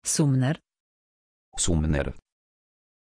Aussprache von Sumner
pronunciation-sumner-pl.mp3